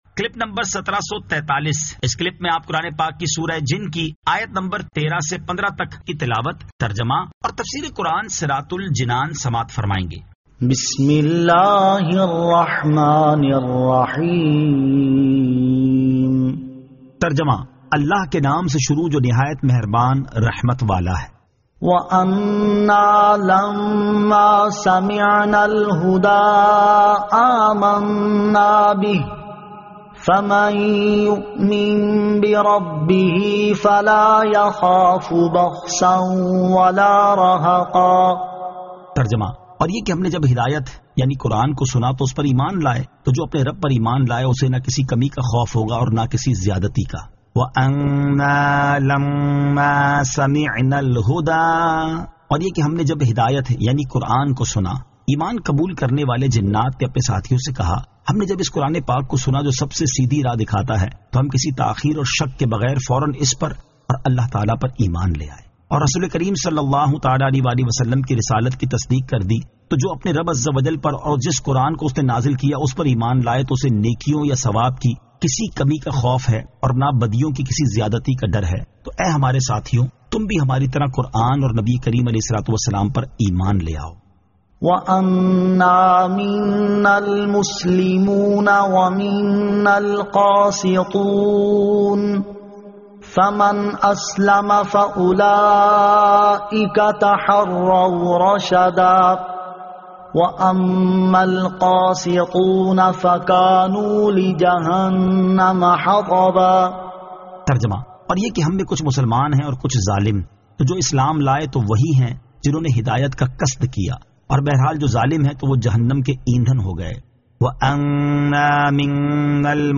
Surah Al-Jinn 13 To 15 Tilawat , Tarjama , Tafseer